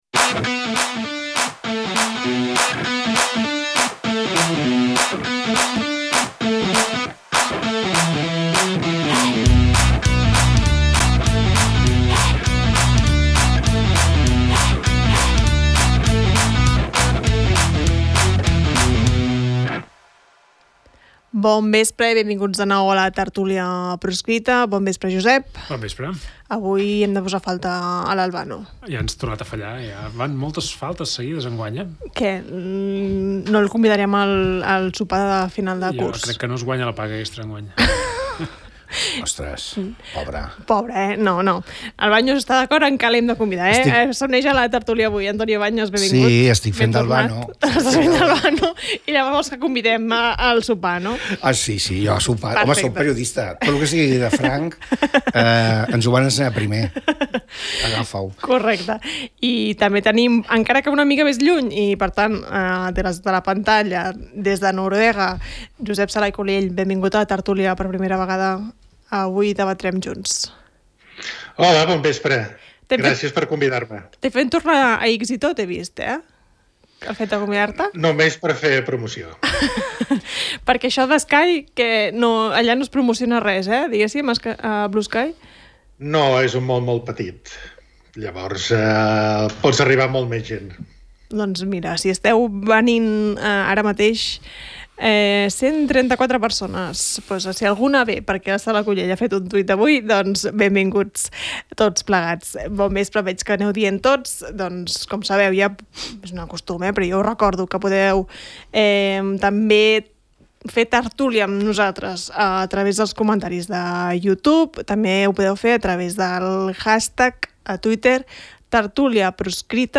Participen en la tertúlia Albano-Dante Fachin